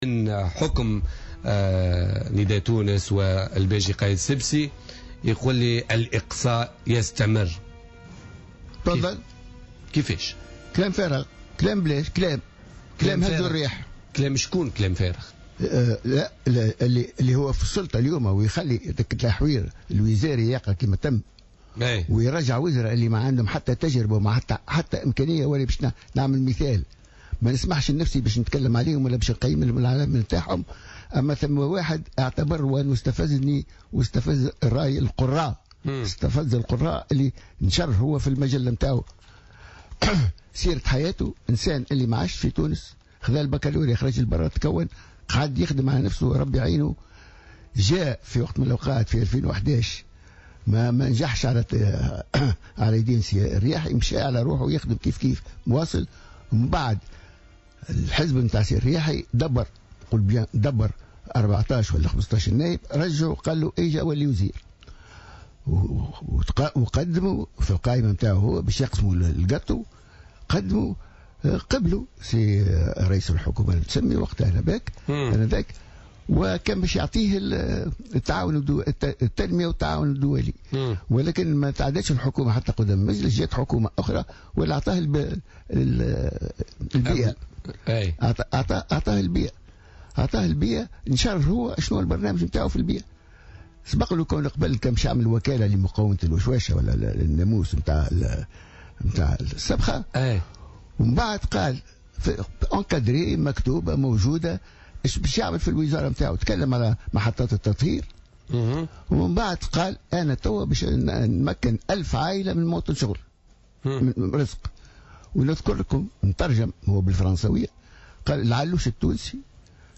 قال حامد القروي رئيس الحركة الوطنية الدستورية والوزير الأول السابق في عهد بن علي ، ضيف برنامج بوليتيكا اليوم الاثنين 08 فيفري 2016 أنه كان متفقا مع الباجي قايد السبسي في تشريك للنهضة في الحكم لعدة أسباب.